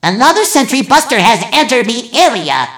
mvm_sentry_buster_alerts06.mp3